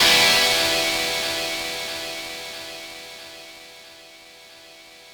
ChordEm.wav